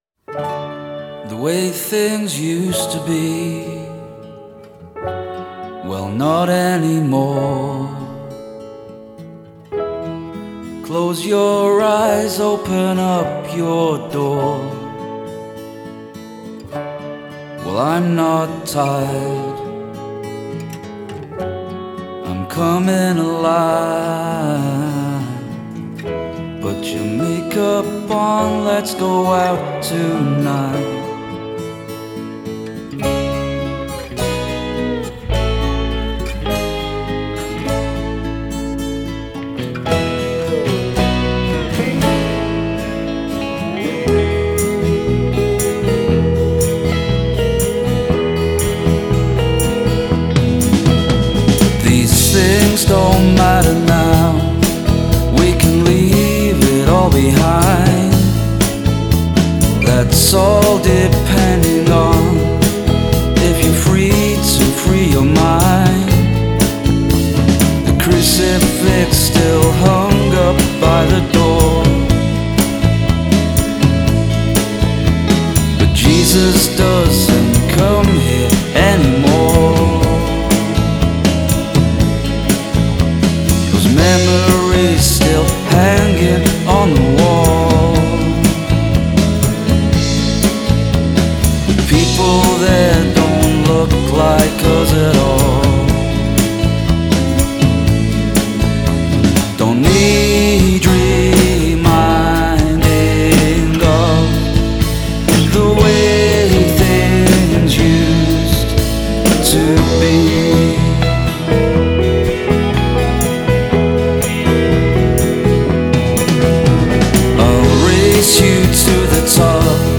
Genre: pop / indie